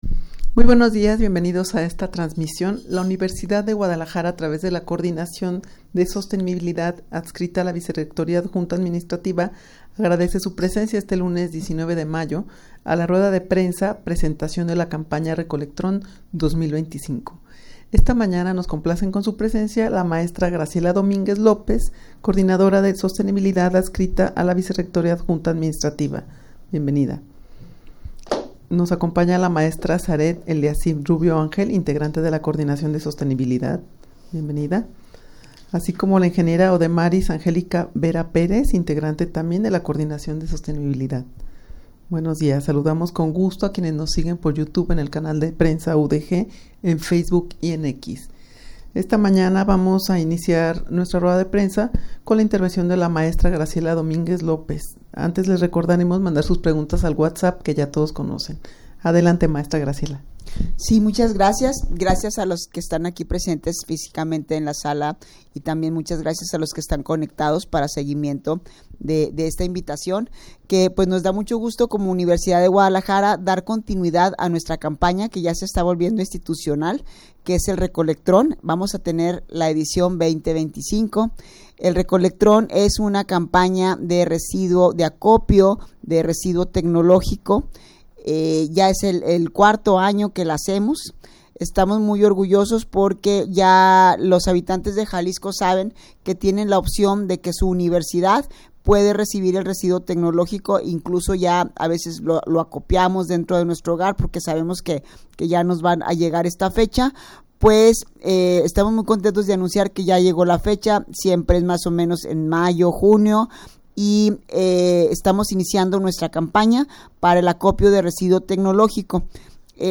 rueda-de-prensa-presentacion-de-la-campana-rec-olectron-2025.mp3